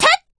p4u-yukari-kick-jp.wav